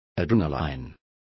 Complete with pronunciation of the translation of adrenaline.